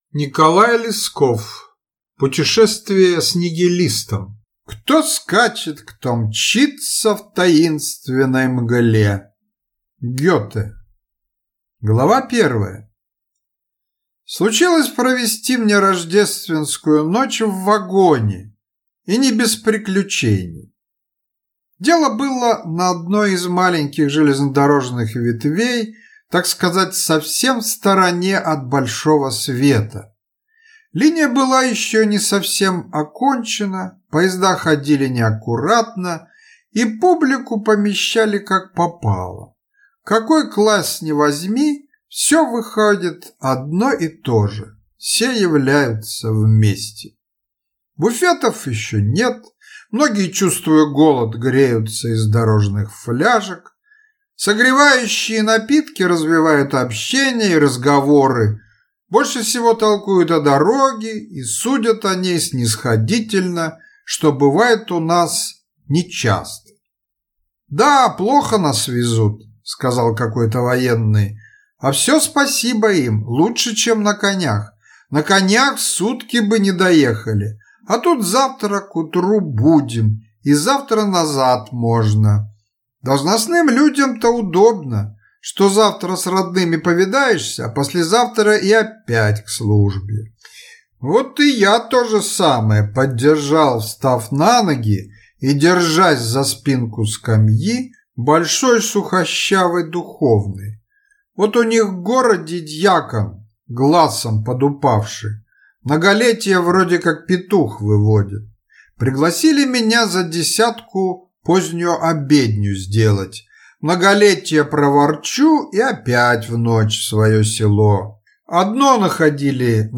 Аудиокнига Путешествие с нигилистом | Библиотека аудиокниг